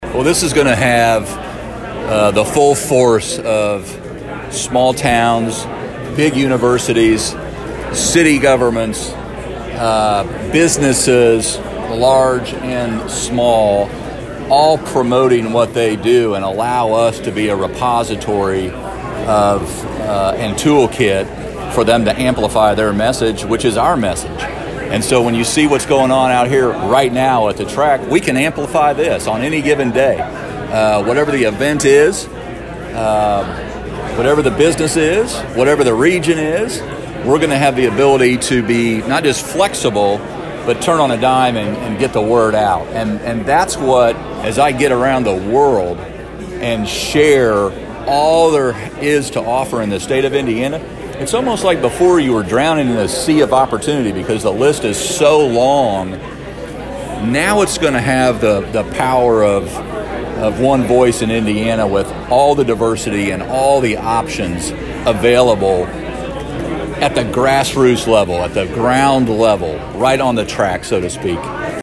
Talking with reporters, Governor Eric Holcomb stated the marketing campaign provides instruments to everybody.